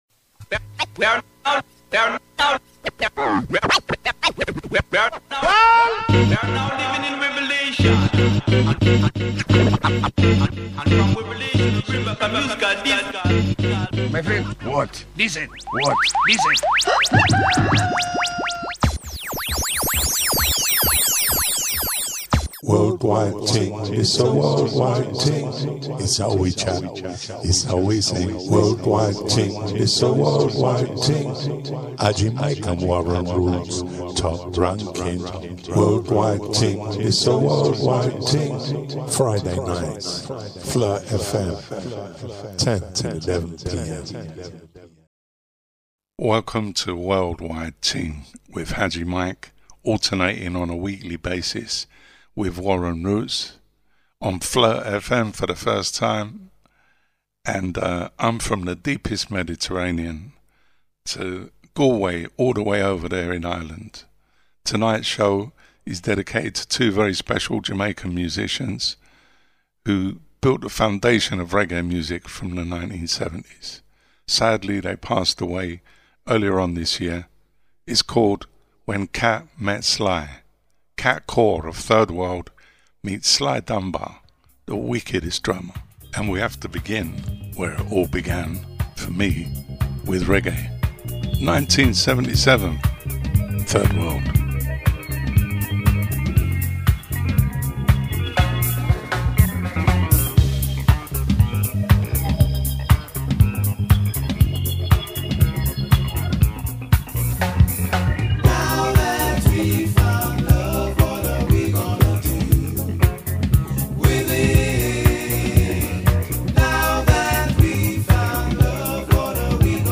A deep musical meditation on drum, bass, arrangement and legacy — from Kingston to Galway and far beyond. Expect heavyweight roots selections, dub excursions and cultural reflection.